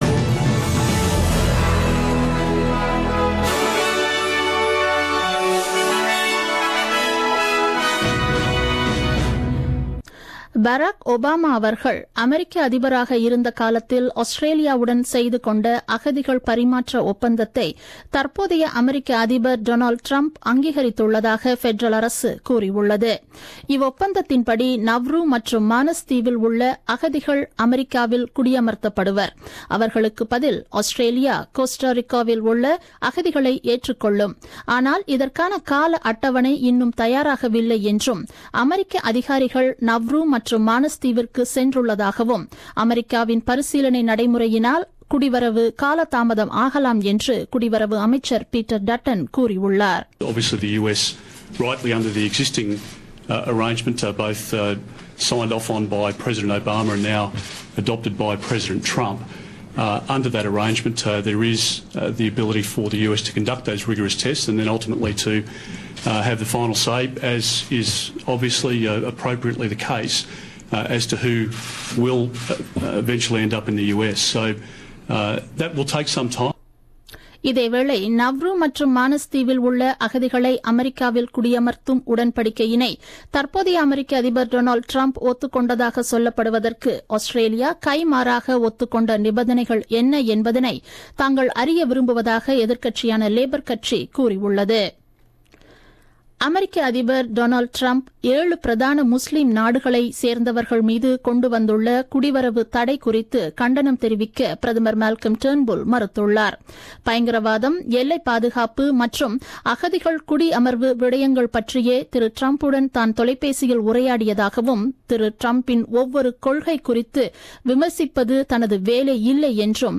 The news bulletin broadcasted on 30 Jan 2017 at 8pm.